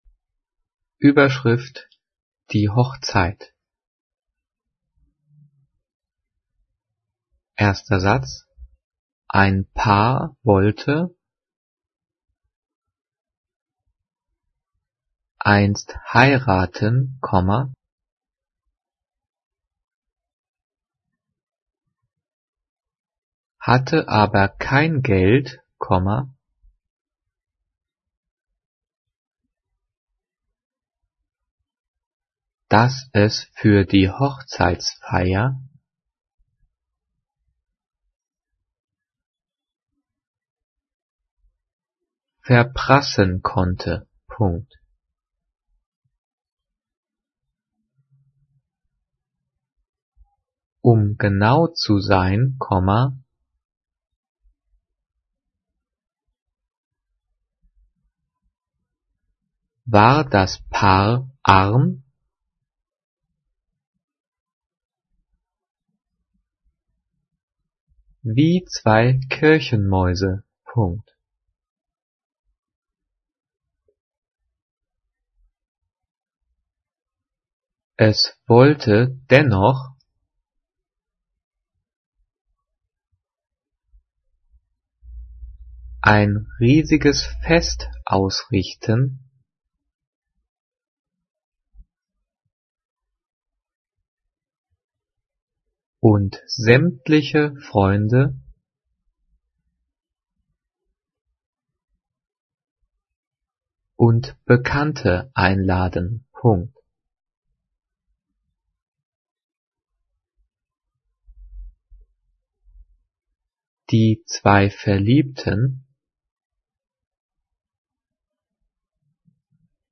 Die vielen Sprechpausen sind dafür da, dass du die Audio-Datei pausierst, um mitzukommen.
Diktiert: